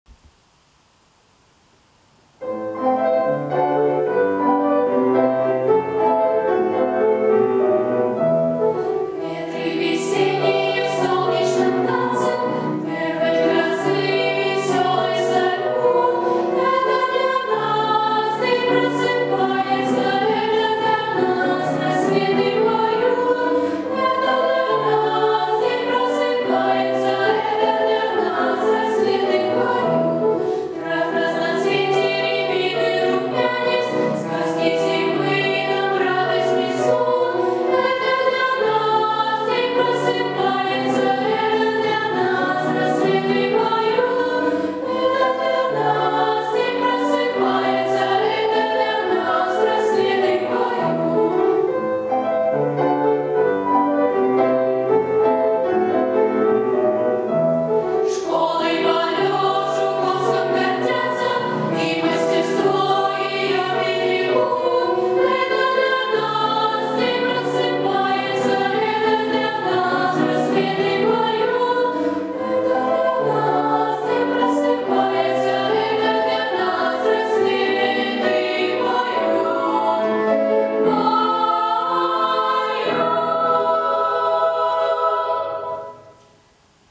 Детская песня
караоке